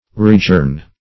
Search Result for " readjourn" : The Collaborative International Dictionary of English v.0.48: Readjourn \Re`ad*journ"\ (r[=e]`[a^]d*j[^u]rn"), v. t. To adjourn a second time; to adjourn again.